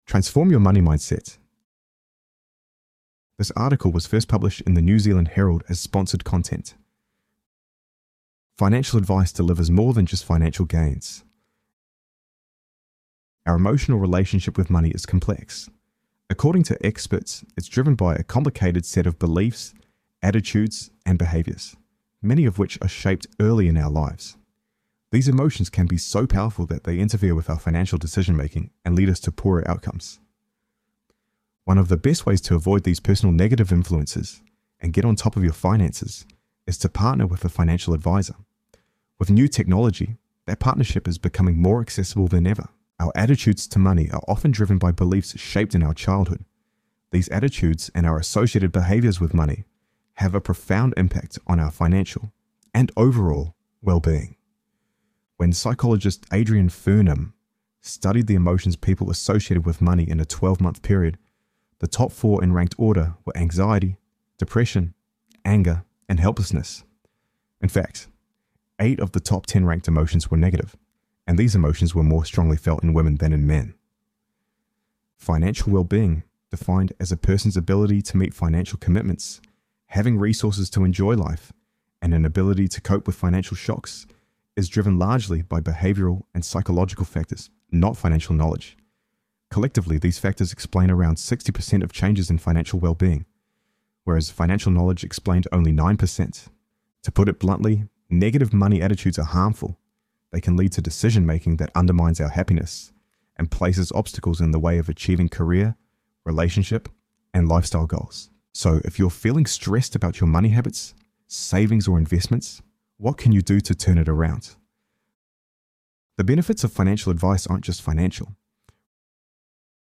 This content features an AI-generated voice for narration purposes.